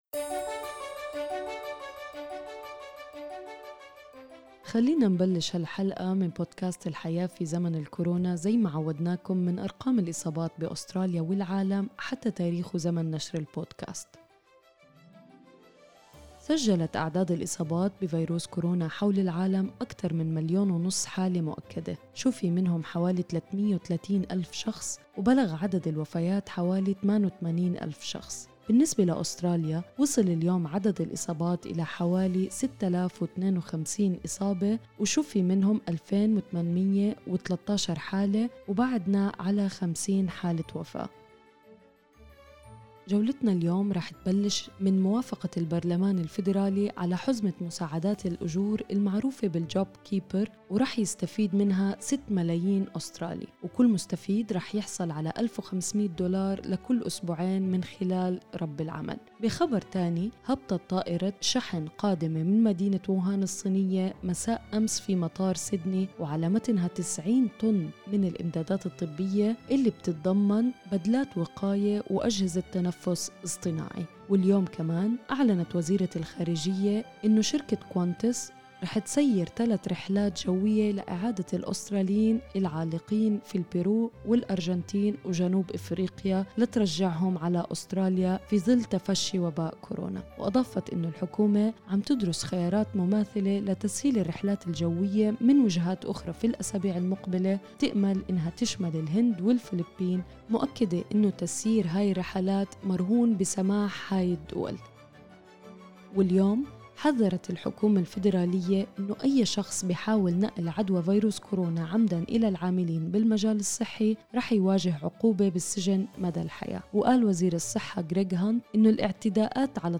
أخبار الكورونا اليوم 9/4/2020